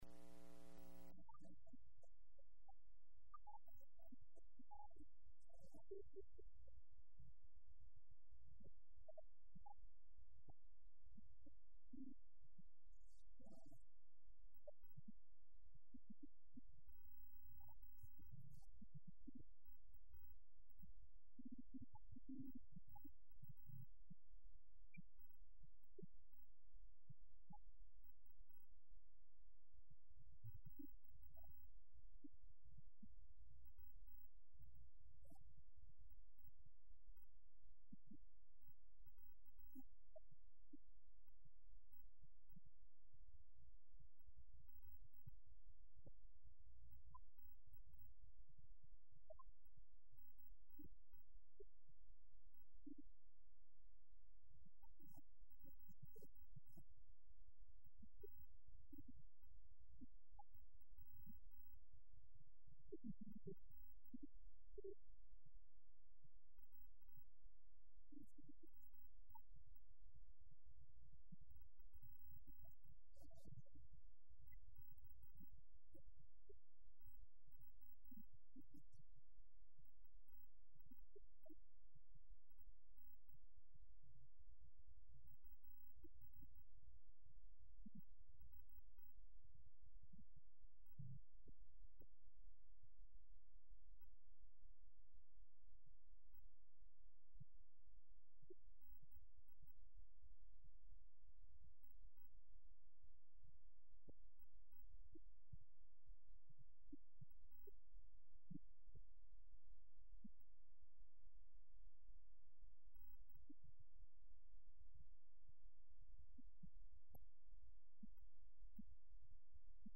6/29/11Wednesday Evening Service